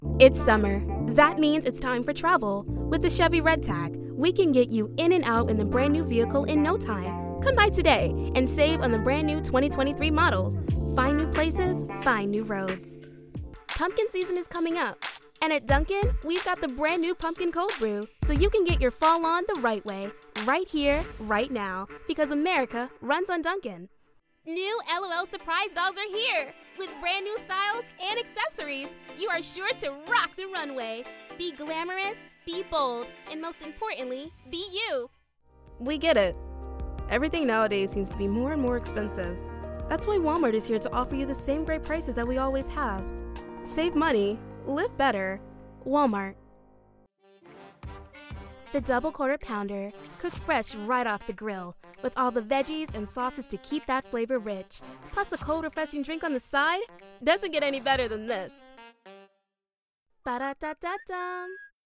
Adult, Young Adult
Millennial, Mom, Girl Next Door/Everdaywoman, Conversational/Real, Cool/Hip/Attitude, Characters, Anime, Storyteller/Folksy, Trustworthy/Gravitas
Home Studio Set -Up 2x2 Treated Closet Space
Microphone: RODE NT 1
black us
new york us
commercial